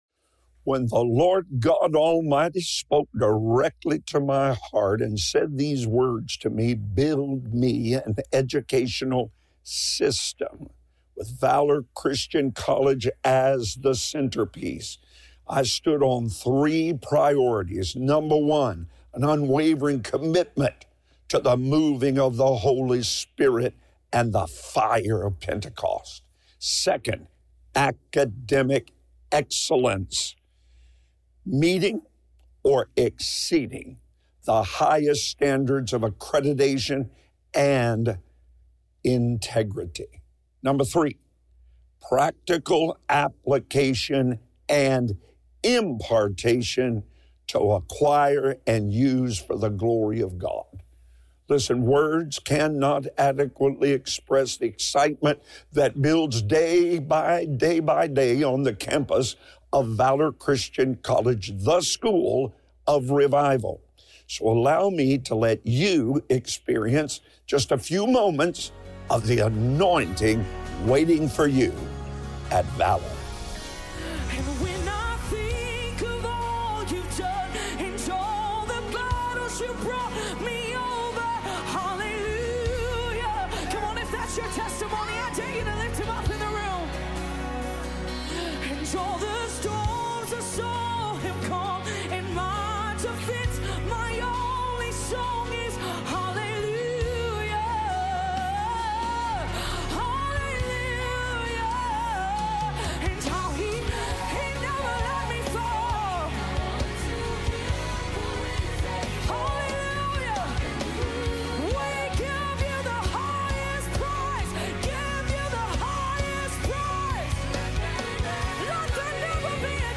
Audio only from the daily television program Breakthrough hosted by Pastor Rod Parsley